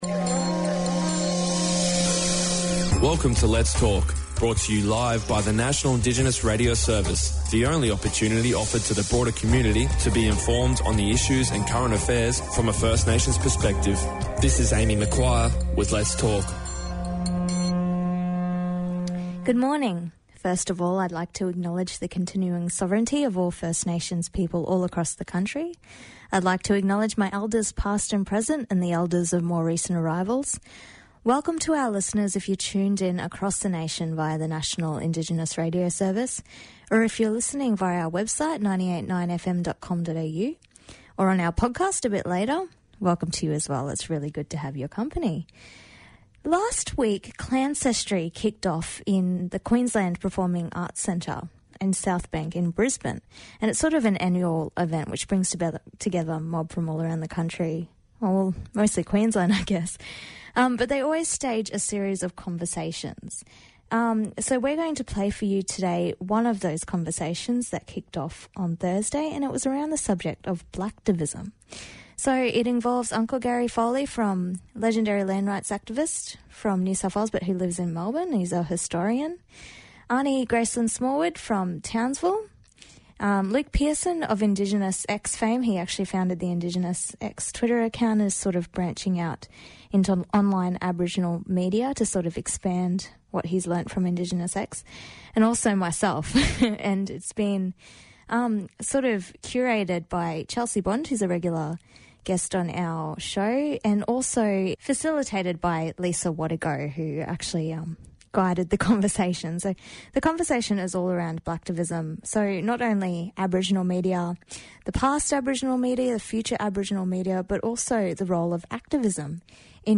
Panellists explore the future of black activism, and the role of Black Media and keyboard warriorship in mobilising the masses in the digital age.”